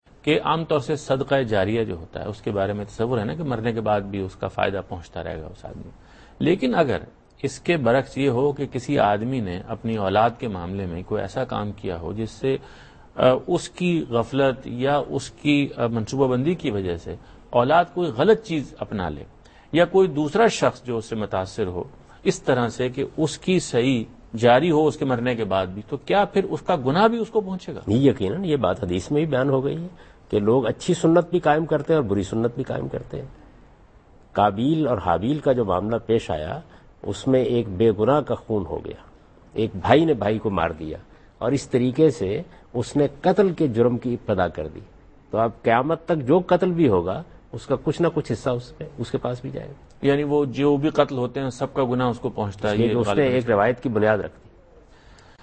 Javed Ahmad Ghamidi answers a question about "Charity " in program Deen o Daanish on Dunya News.
جاوید احمد غامدی دنیا نیوز کے پروگرام دین و دانش میں صدقہ جاریہ سے متعلق ایک سوال کا جواب دے رہے ہیں۔